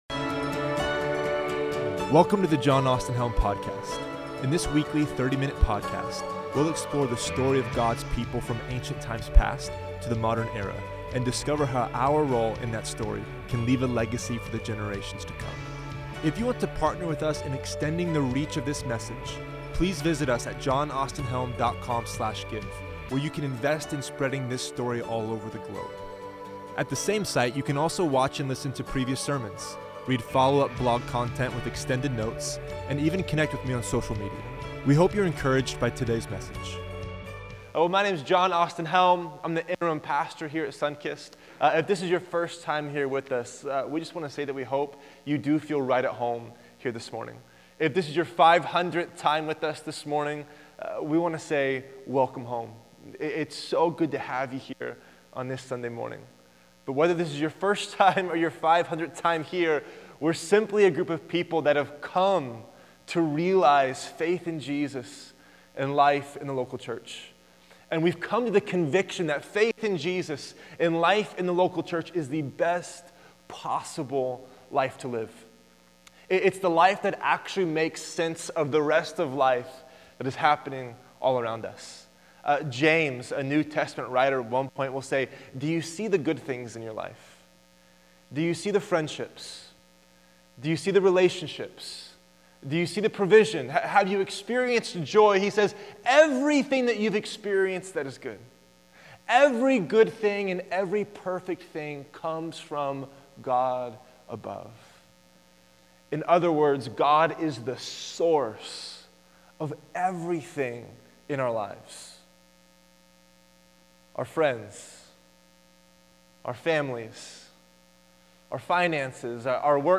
Recorded live at Sunkist Church on Sunday, May 21, 2017.